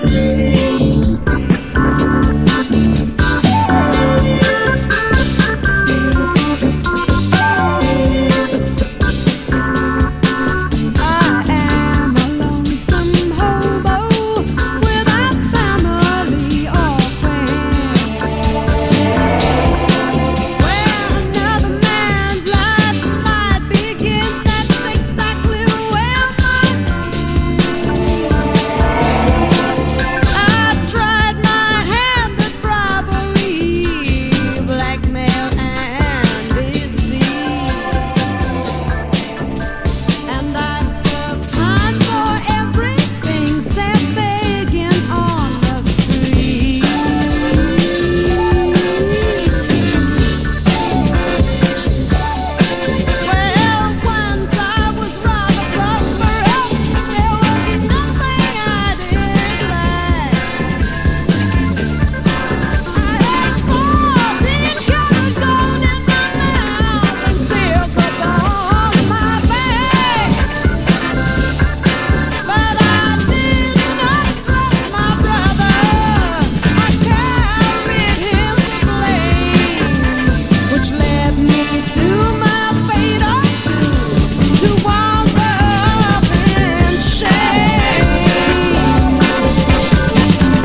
ROCK / 60'S / MOD JAZZ / JAZZ ROCK / PSYCHEDELIC
FREE SOUL/ACID JAZZ期に再評価されたオルガン・グルーヴ！